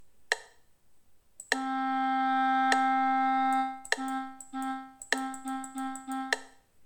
É moi importante que escoitedes a claqueta de fondo (ou metrónomo), para levar a conta do número de pulsos que ocupa cada son e poder asocialo a unha figura musical determinada.